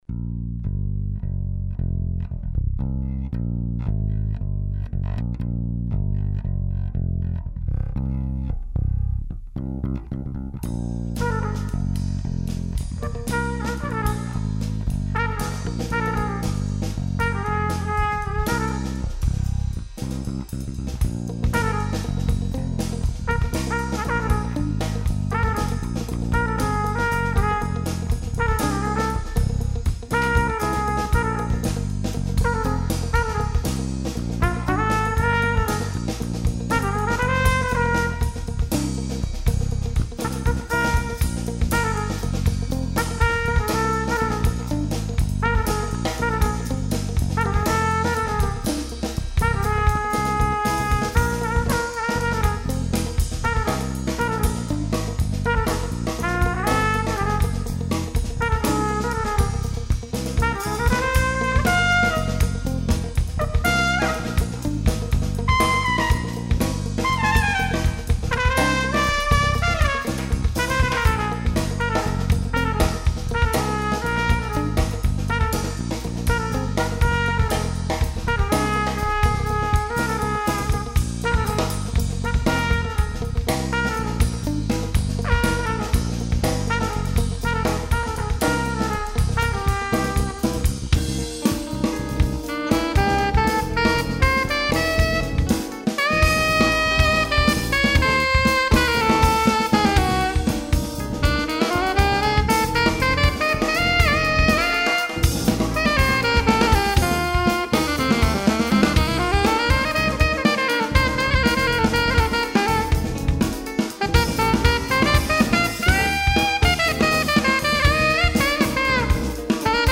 tromba, composizione, arrangiamento, direzione